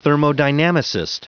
Prononciation du mot thermodynamicist en anglais (fichier audio)
Prononciation du mot : thermodynamicist